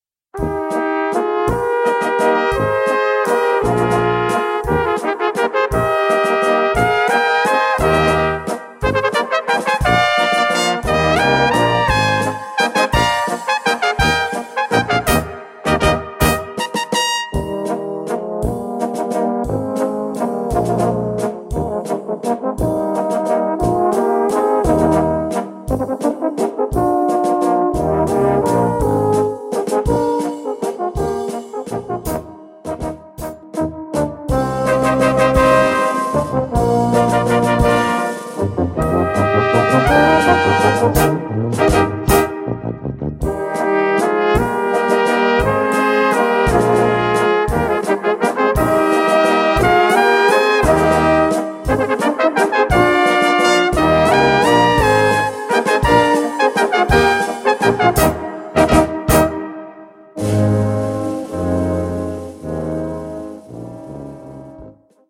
Blasorchester
Ein schwungvoller Walzer